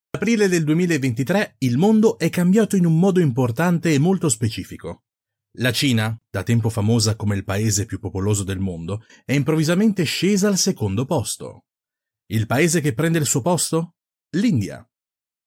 意大利语样音试听下载
意大利语配音员（男4）